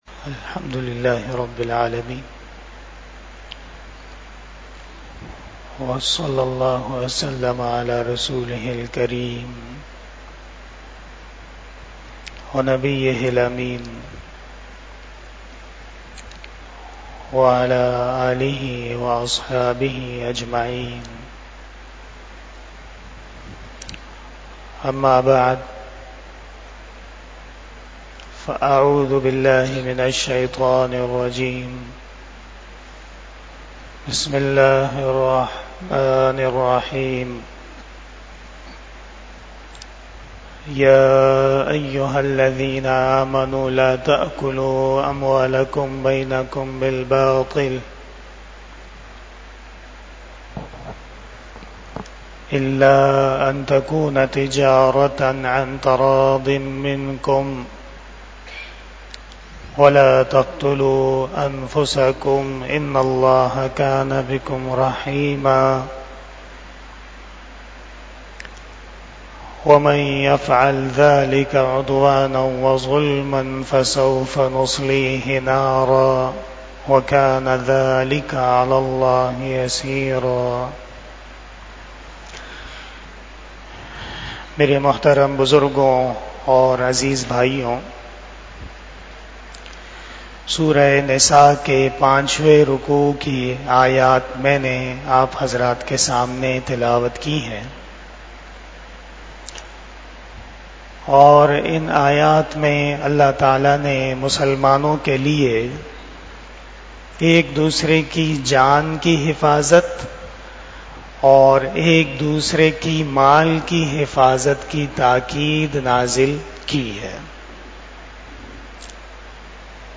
20 Shab E Jummah Bayan 20 June 2024 (14 Zil Hajjah 1445 HJ)